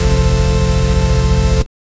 Mystical hum layered for mysterious, powerful Arabian vibe. 0:10 Soul pull like looping audio 0:02 Bubbling Cauldron 0:10
soul-pull-like-looping-au-ja55njjf.wav